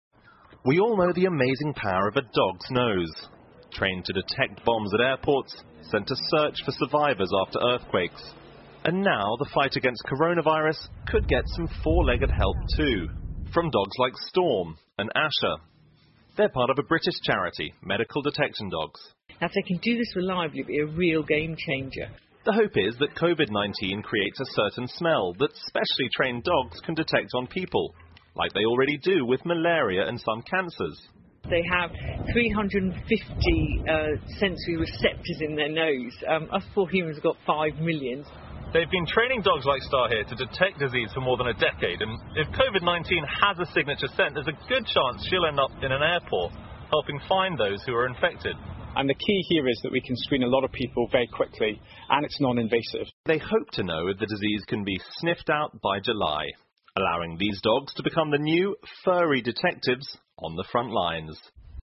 NBC晚间新闻 训练犬有望帮助探测新冠病毒 听力文件下载—在线英语听力室